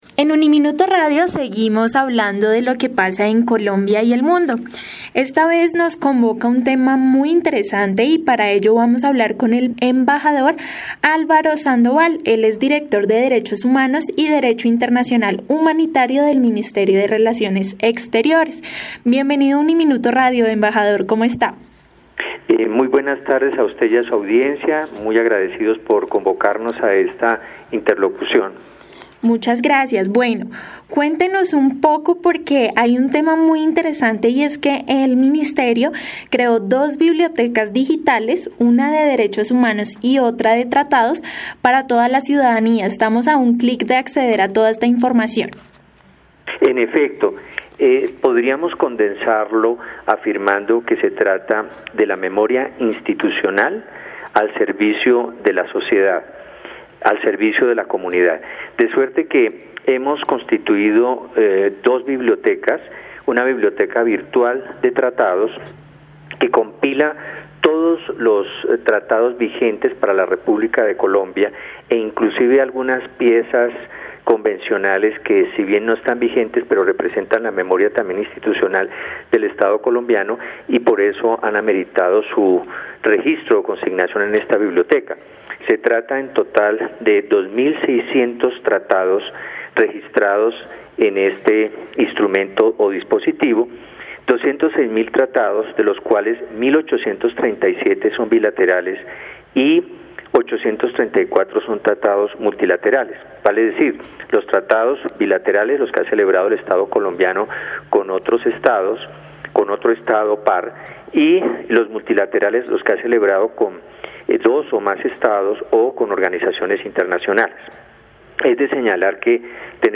Entrevista-Embajador.mp3